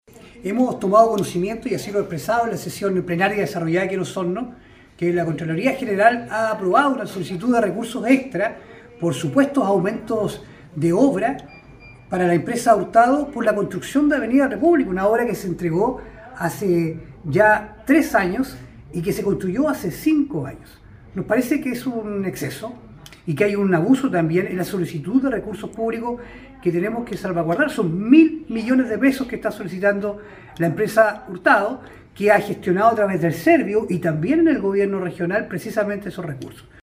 En la última sesión plenaria del Consejo Regional el core por la provincia de Osorno, Francisco Reyes Castro, dijo que fiscalizará cada peso extra que está solicitando la empresa ejecutora de la obra Avenida República, recepcionada con excesivo retraso el 2021, pese a que la importante vía está en marcha desde 2018, hace más de 5 años.